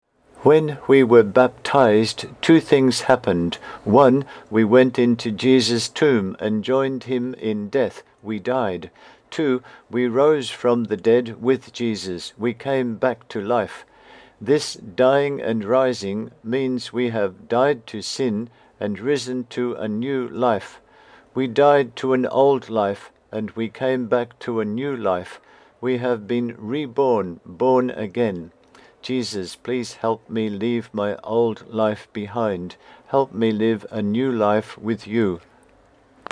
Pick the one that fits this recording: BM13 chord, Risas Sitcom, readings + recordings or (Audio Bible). readings + recordings